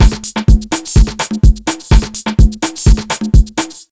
126BEAT1 3-L.wav